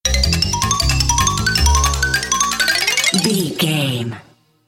Short music, corporate logo or transition between images,
Fast paced
In-crescendo
Uplifting
Ionian/Major
cheerful/happy
futuristic
industrial
powerful
groovy
funky
synthesiser